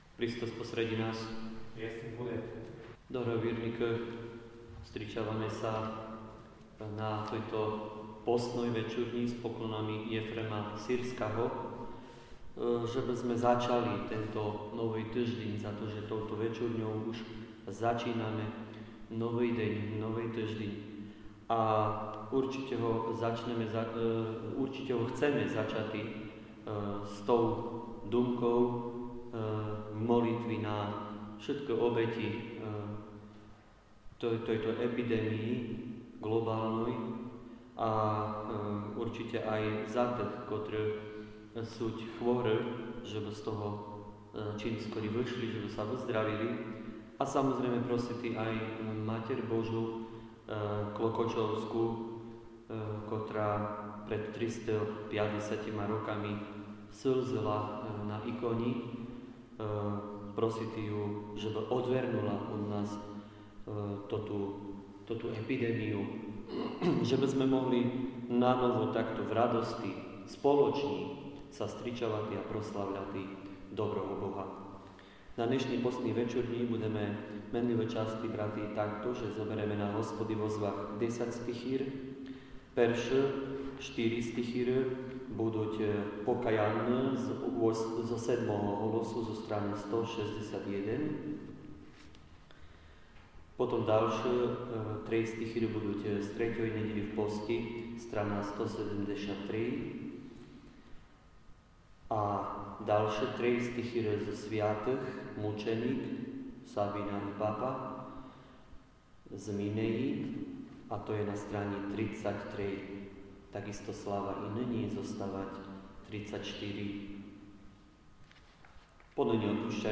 VEČURŇA 15.3.2020